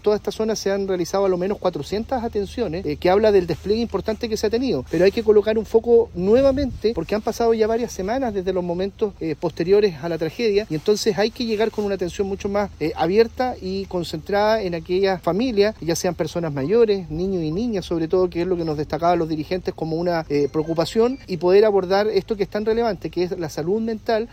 El delegado Presidencial del Bío Bío, Eduardo Pacheco, detalló que el despliegue se reforzó tras detectar una alta necesidad de contención emocional, especialmente en familias que perdieron sus viviendas.